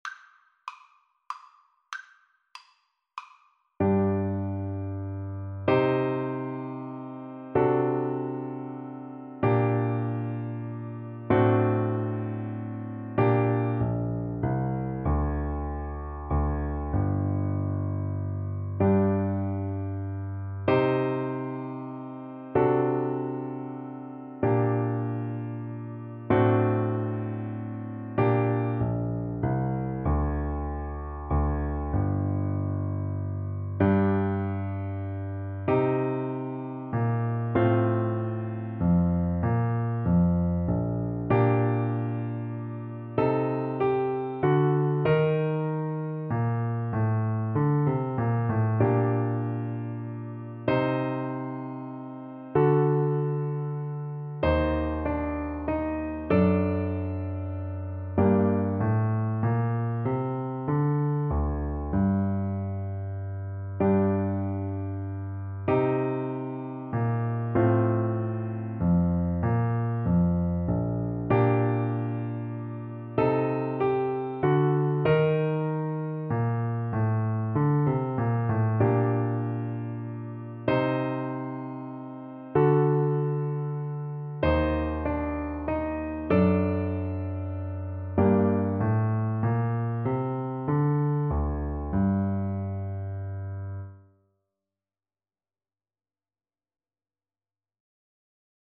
Play (or use space bar on your keyboard) Pause Music Playalong - Piano Accompaniment Playalong Band Accompaniment not yet available reset tempo print settings full screen
G minor (Sounding Pitch) E minor (Alto Saxophone in Eb) (View more G minor Music for Saxophone )
3/8 (View more 3/8 Music)
Classical (View more Classical Saxophone Music)